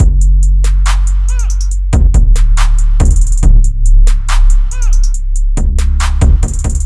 停滞不前 陷阱大鼓
标签： 140 bpm Trap Loops Drum Loops 1.15 MB wav Key : Unknown
声道立体声